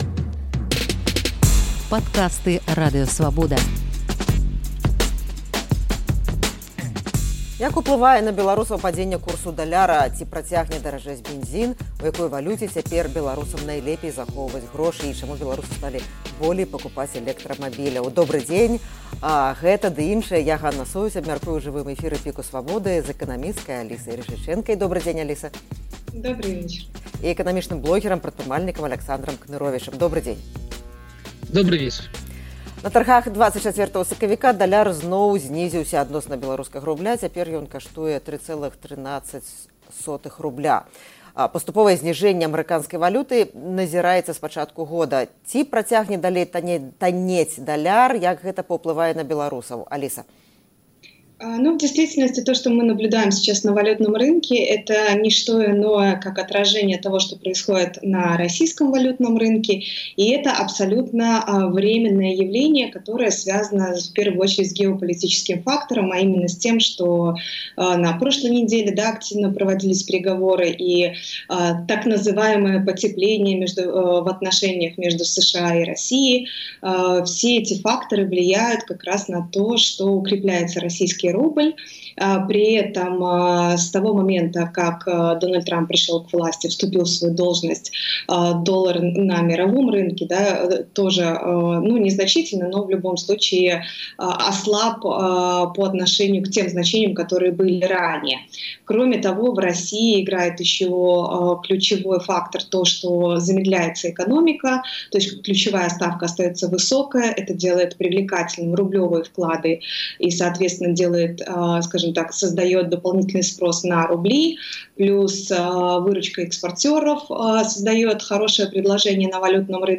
абмярковае ў жывым эфіры